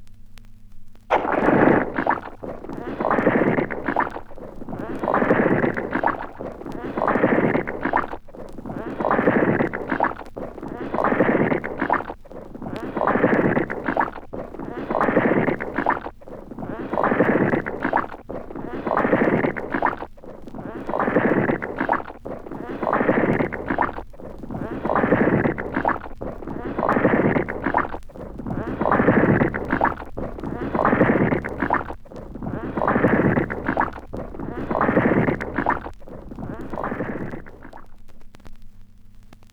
• squelching footsteps.wav
Recorded from Sound Effects - Death and Horror rare BBC records and tapes vinyl, vol. 13, 1977.
squelching_footsteps_uY8.wav